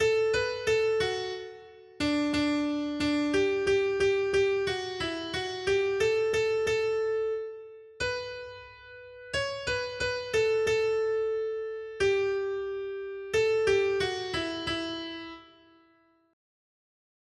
Noty Štítky, zpěvníky ol186.pdf responsoriální žalm Žaltář (Olejník) 186 Ž 33, 4-6 Ž 33, 9 Ž 33, 18-20 Ž 33, 22 Skrýt akordy R: Blaze lidu, který si Hospodin vyvolil za svůj majetek. 1.